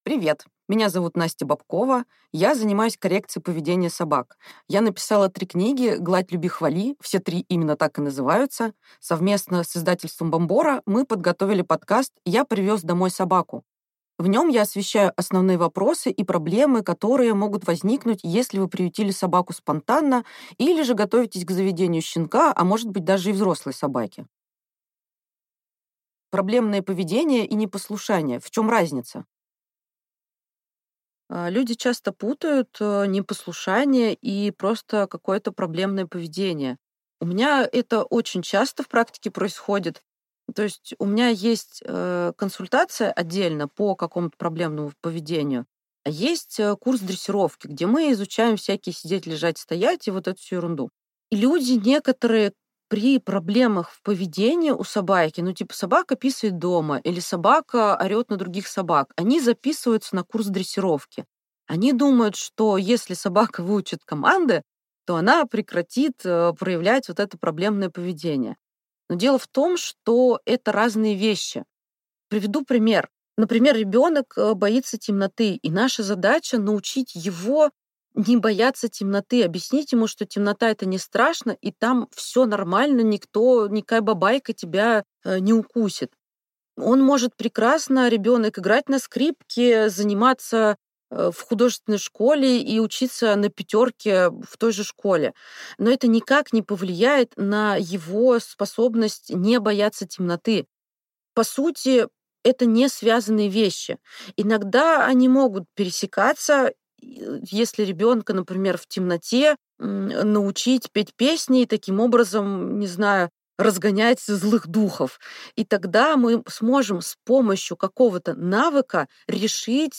Аудиокнига Проблемное поведение и непослушание: в чем разница | Библиотека аудиокниг